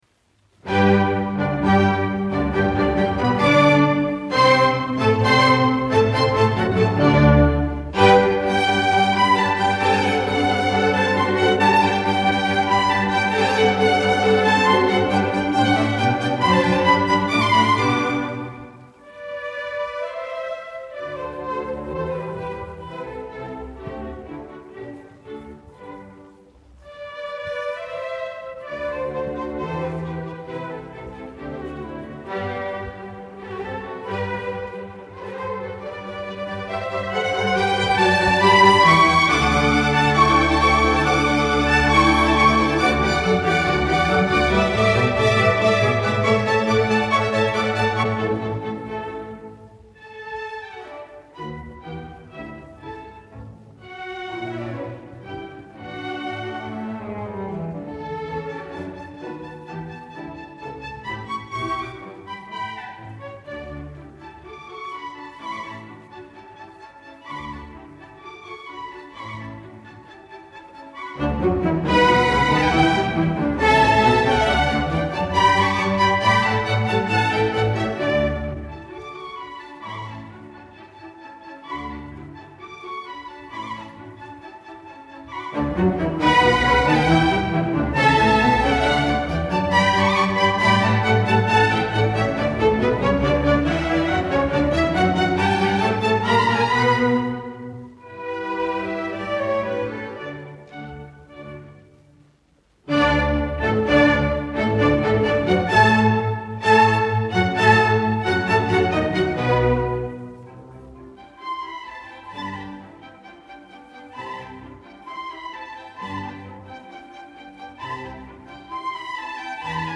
Когда ребята собирают из конструктора параллелепипед, играем музыка (